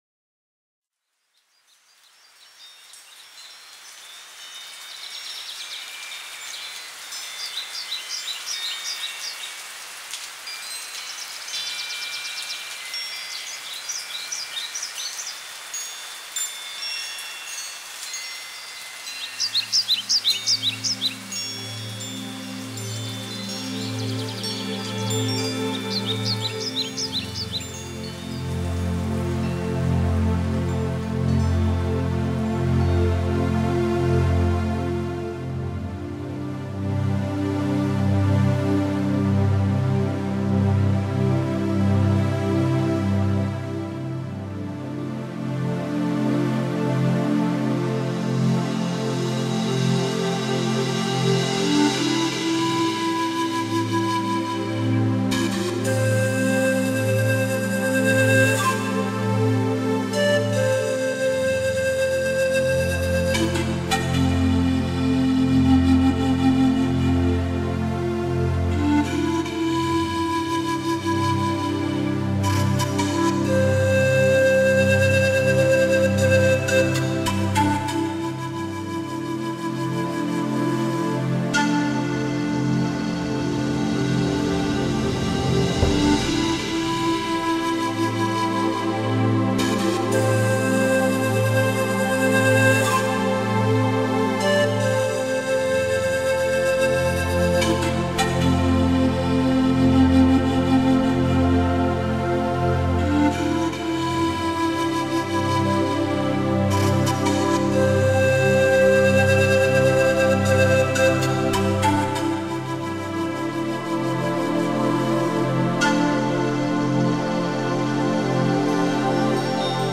Musique de relaxation, la pluie, chants d’oiseaux
MUSIQUE RELAXANTE, EFFETS SONORES DE LA NATURE